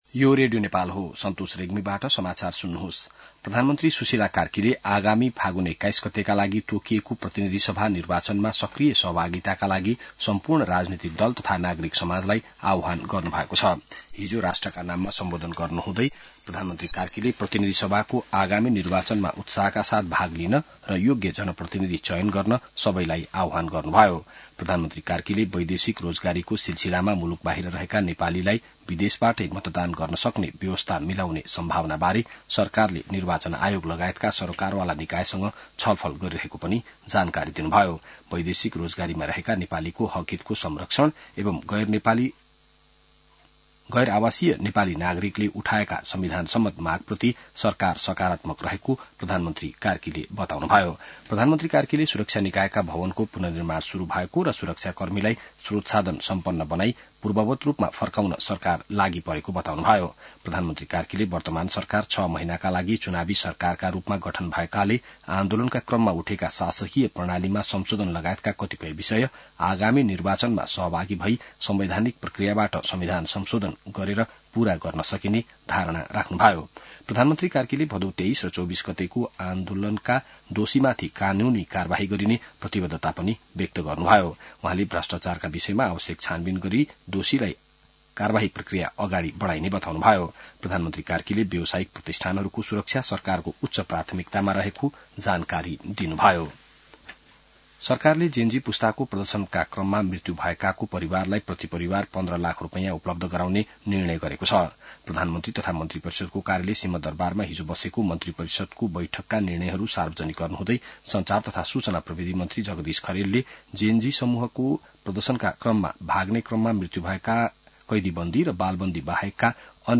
An online outlet of Nepal's national radio broadcaster
बिहान ६ बजेको नेपाली समाचार : १० असोज , २०८२